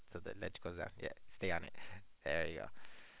Prosodic Patterns in English Conversation
audio examples for Chapter 7: Expressing Positive Assessment